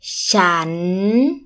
∨ shann